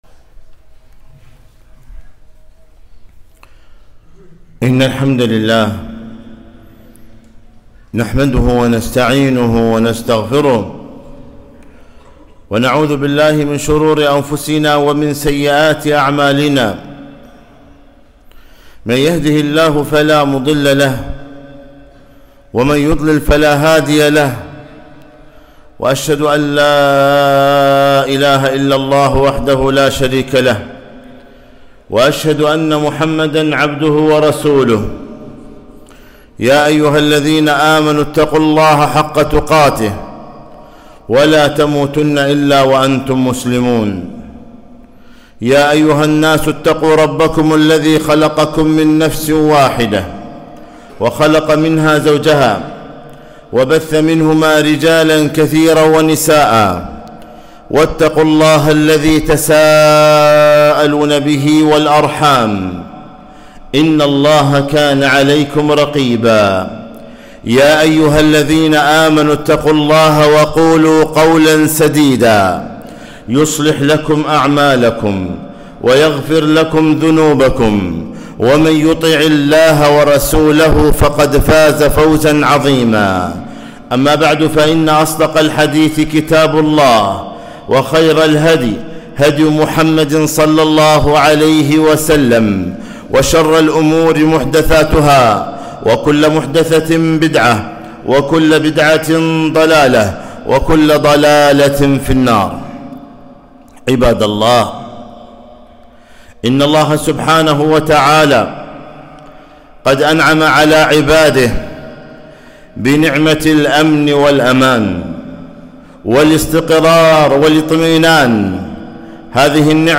خطبة - السلطان ظل الله في الأرض